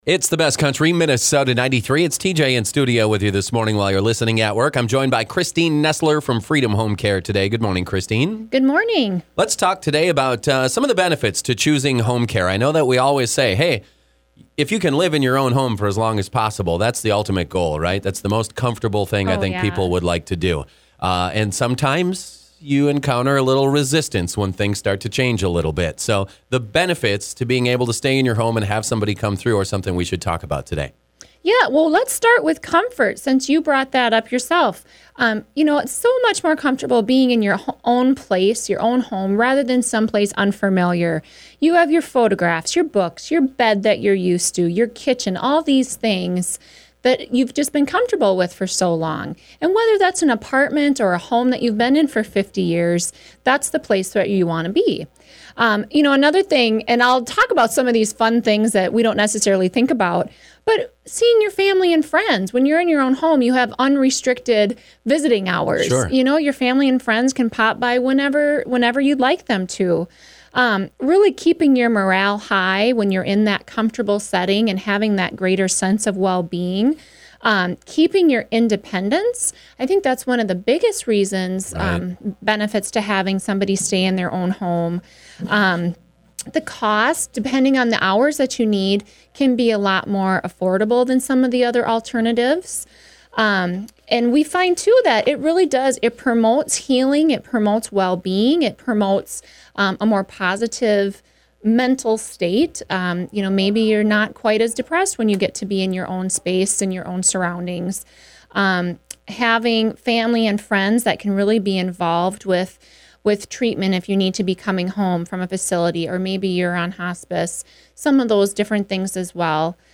Live interviews are aired on Thursday mornings between 9:00 a.m. to 9:20 a.m. on 93.1 FM.